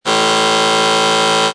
buzer.mp3